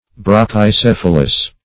Search Result for " brachycephalous" : The Collaborative International Dictionary of English v.0.48: Brachycephalic \Brach`y*ce*phal"ic\, Brachycephalous \Brach`y*ceph"a*lous\, a. [Gr. brachy`s short + ? head.]
brachycephalous.mp3